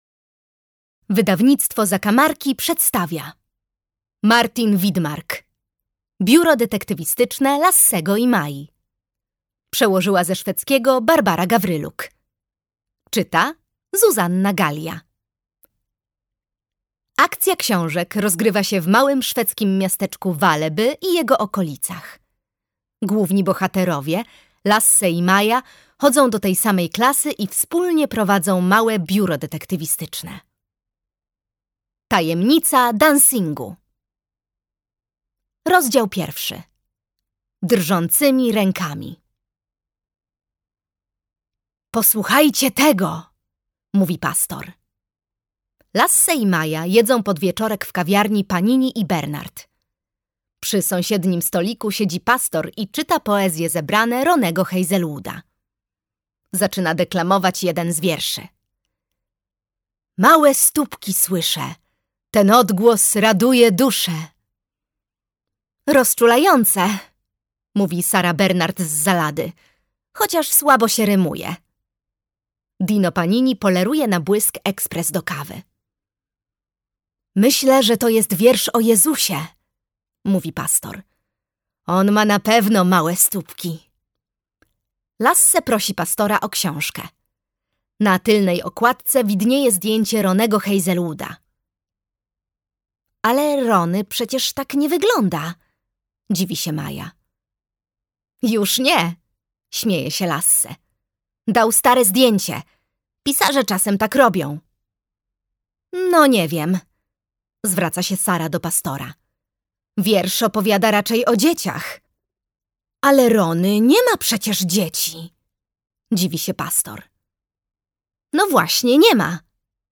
Biuro Detektywistyczne Lassego i Mai. Tajemnica dancingu - Martin Widmark - audiobook